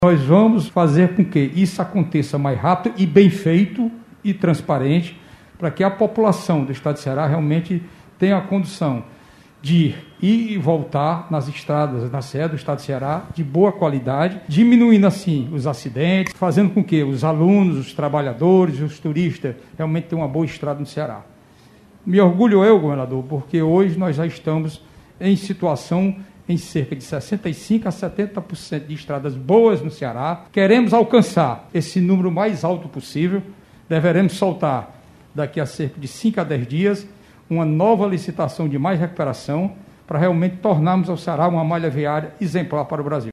Segundo o superintendente da SOP, Quintino Vieira, o Ceará já conta atualmente com algo entre 65 e 70% de estradas em boas condições de uso.